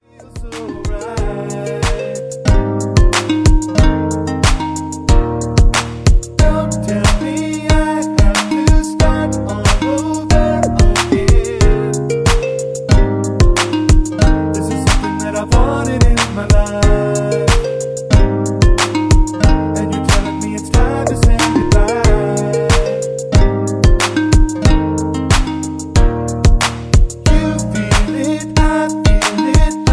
(Key-Ab) Karaoke MP3 Backing Tracks
Just Plain & Simply "GREAT MUSIC" (No Lyrics).